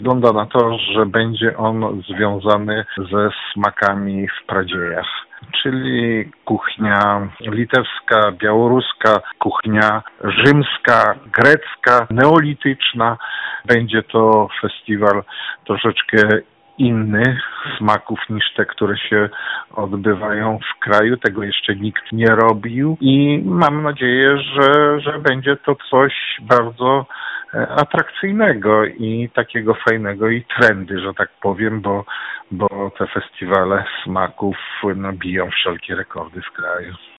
podczas ostatniej sesji Rady Powiatu Żninskiego